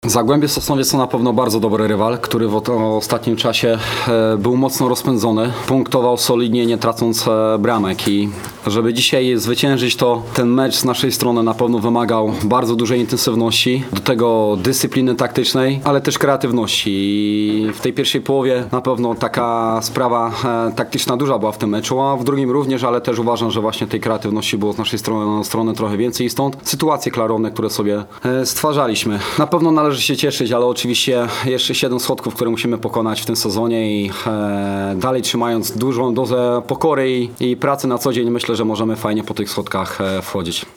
powiedział na pomeczowej konferencji